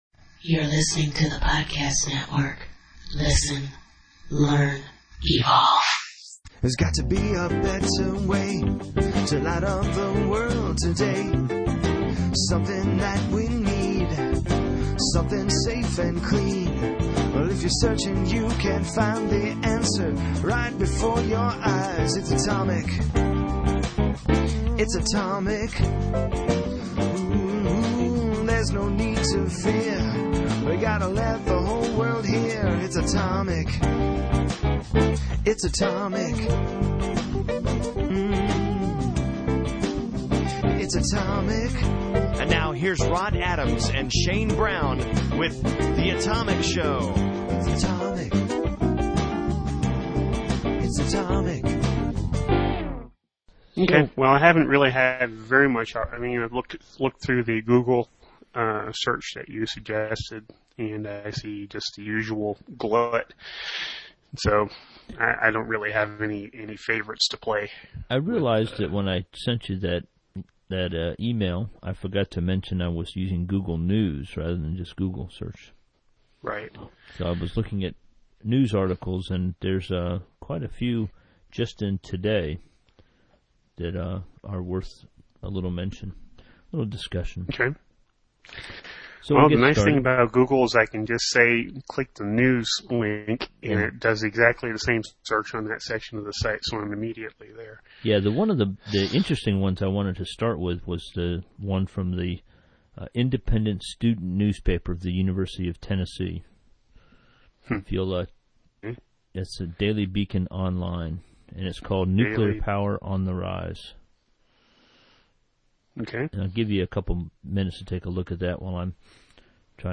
We hope you do not get frustrated in following our chat, but that is the way that conversations go at times.